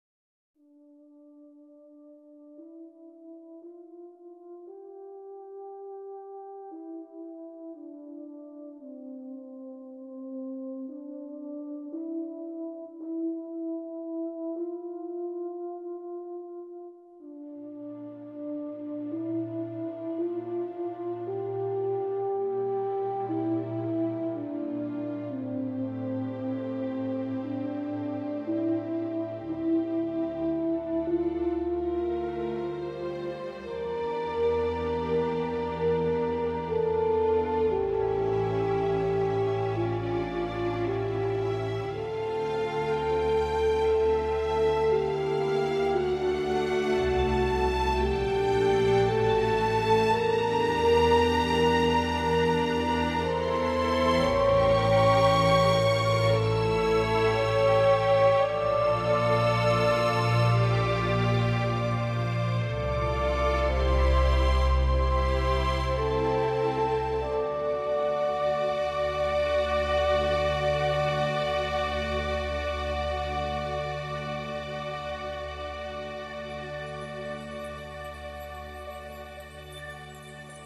低沉的打击乐仿佛敲响了永乐朝的大钟
梦醒时分，大幕落下，看尽世事，洗尽铅华，带着丝丝感伤、点点无奈，乐声渐渐隐去。